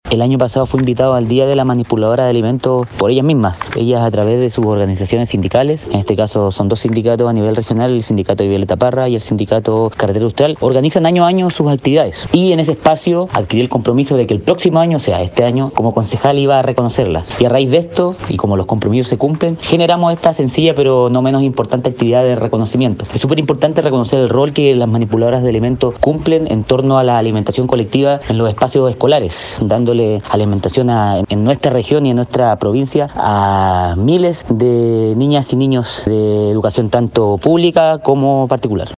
Respecto a esta actividad el Concejal Franco Ojeda, señaló: